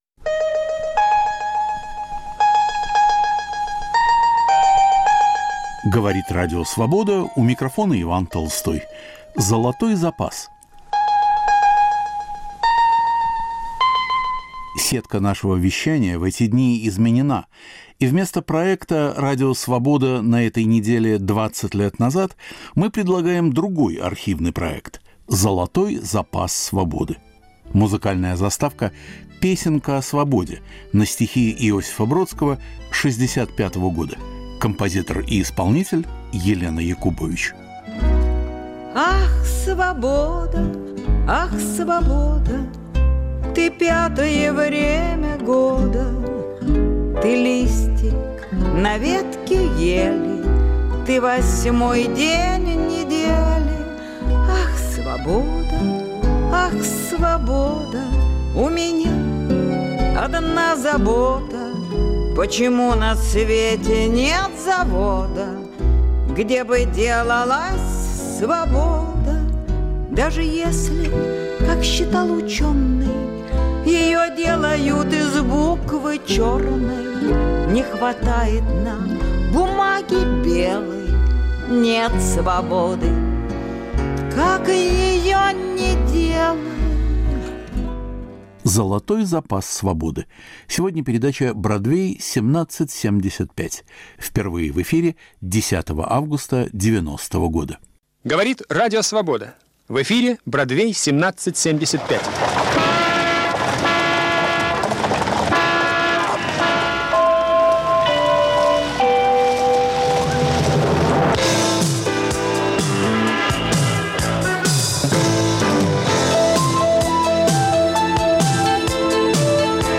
Встреча бардов на высшем уровне, и многое другое в передаче из нью-йоркской студии Радио Свобода.